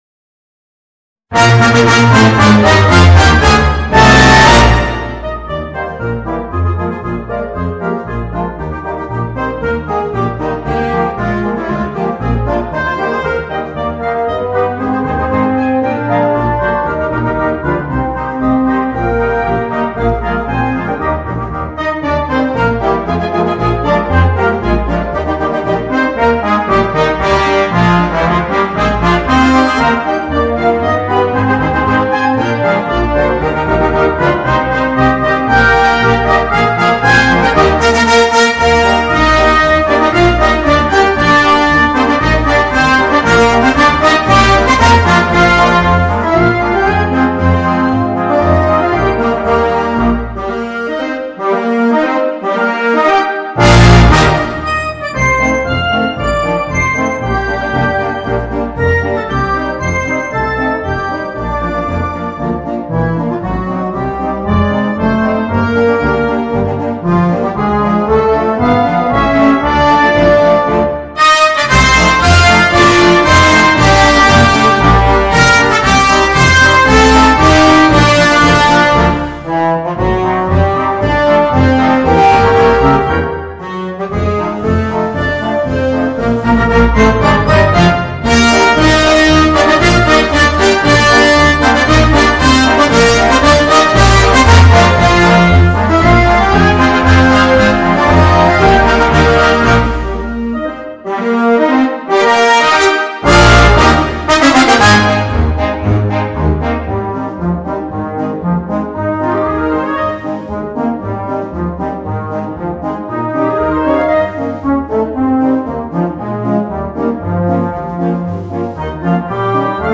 Voicing: Concert Band - Blasorchester - Harmonie